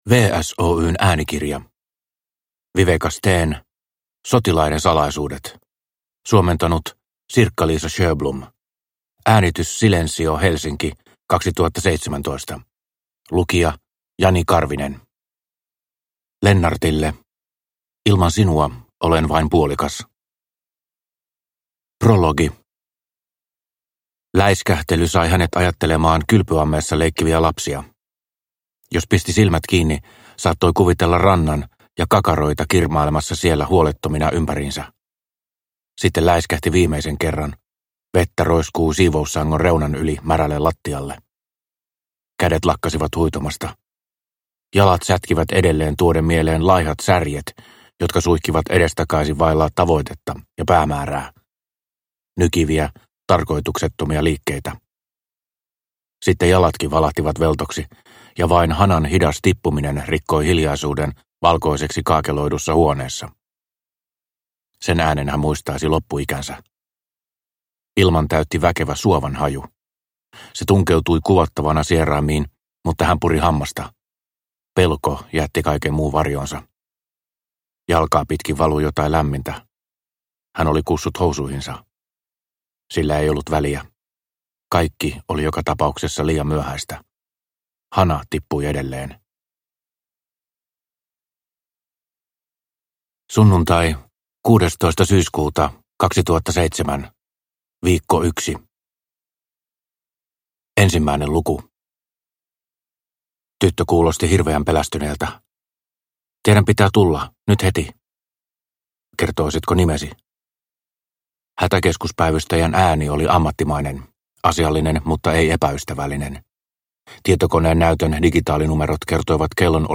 Sotilaiden salaisuudet – Ljudbok – Laddas ner